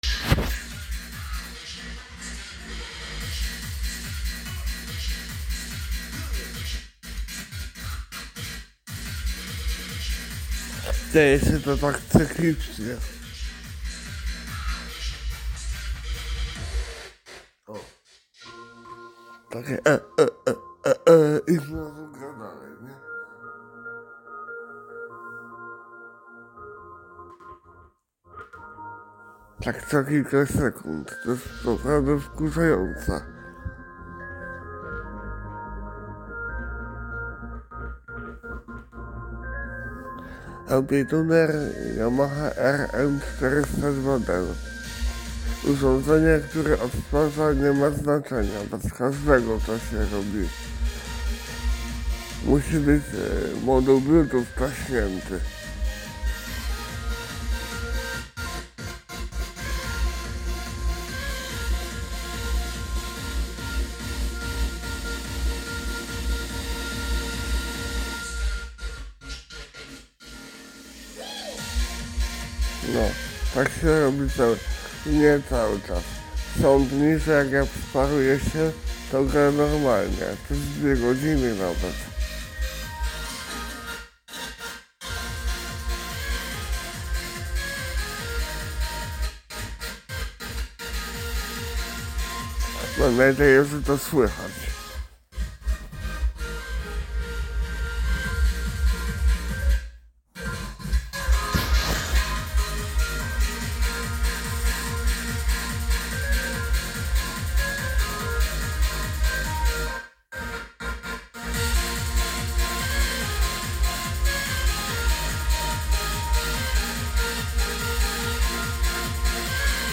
Nie zależnie z jakiego urządzenia to kdtwarzan, tak rwie co kilka sekund.
To takie cykliczne, jakby zakłócało, potem chwila spokój i nów i tak w kółko.
To co wkleiłeś to typowe przerywanie BT.